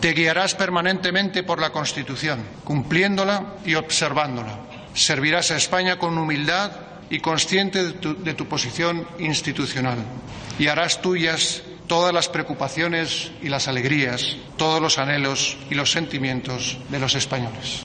Ante una mesa donde se exponían el Collar, la credencial de ingreso en la Orden y el libro de sus ordenanzas, Felipe VI ha prendido el lazo con la miniatura del vellocino, símbolo del Toisón de Oro, en el vestido de su primogénita, y a continuación ha dirigido unas palabras desde el atril.